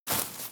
GravelStep5.wav